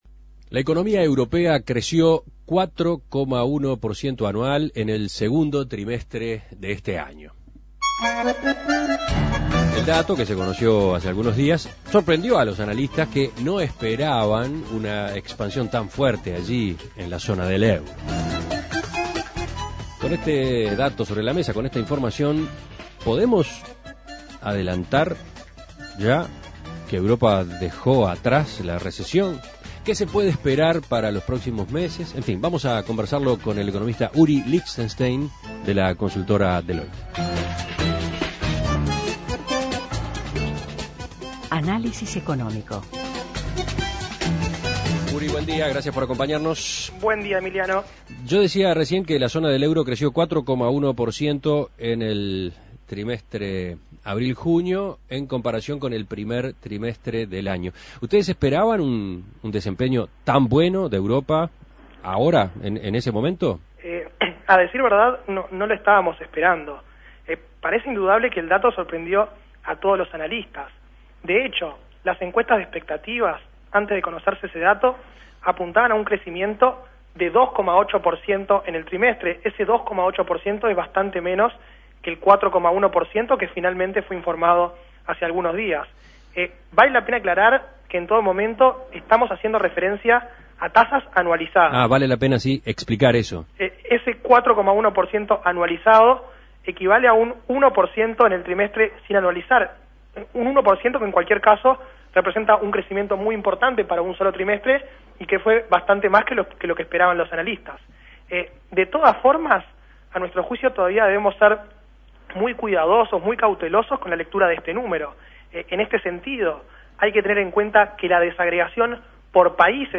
Análisis Económico La zona del euro creció 4,1% en abril-junio.